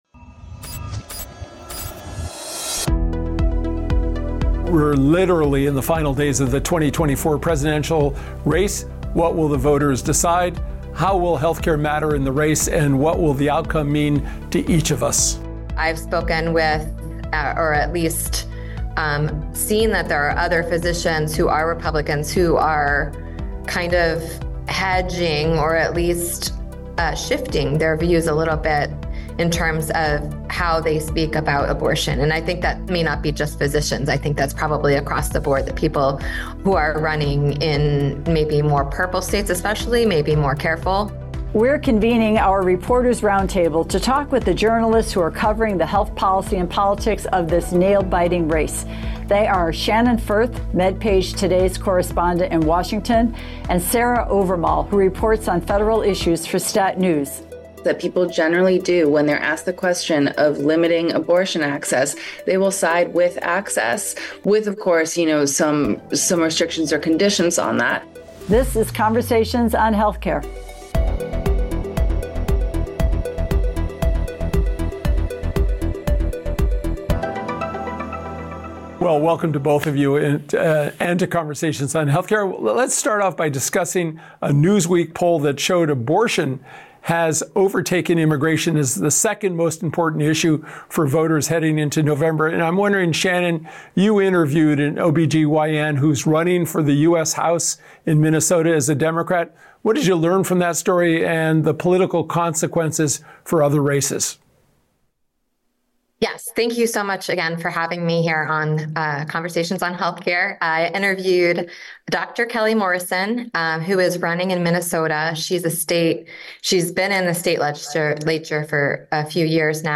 Our guests are the reporters covering these issues.